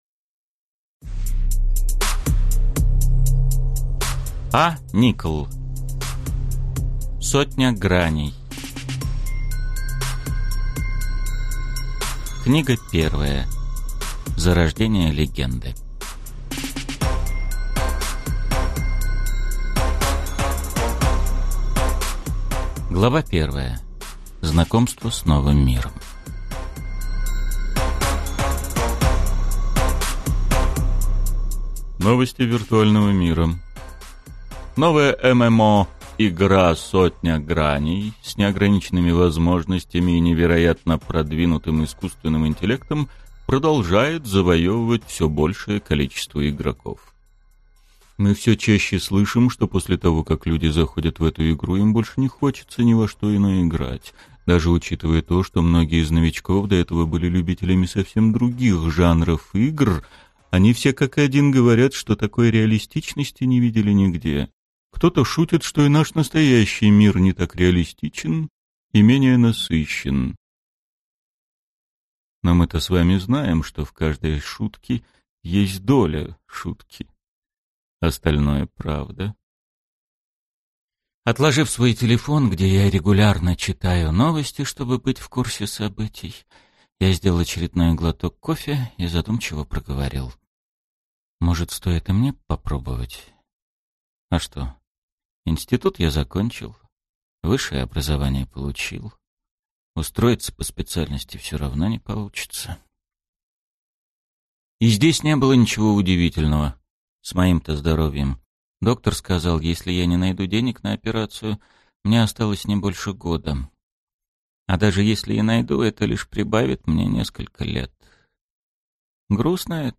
Аудиокнига Сотня граней. Книга 1. Зарождение легенды | Библиотека аудиокниг